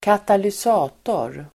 Uttal: [²katalys'a:tor]